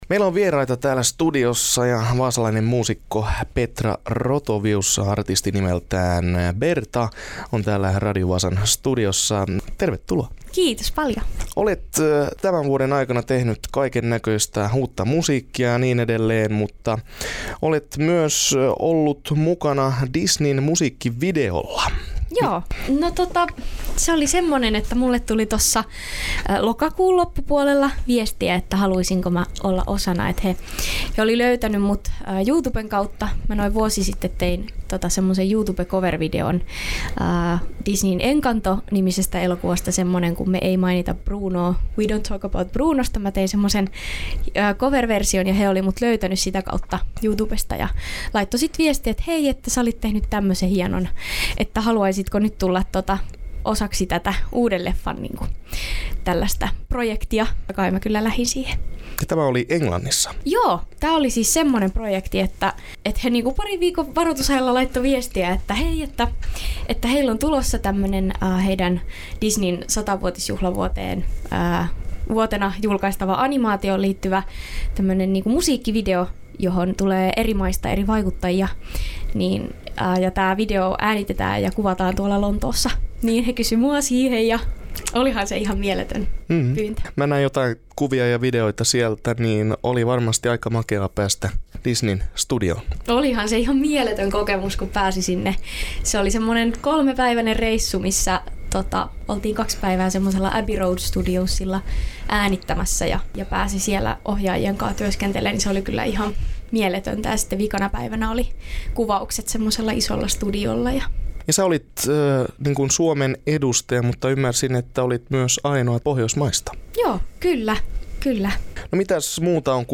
vieraili studiolla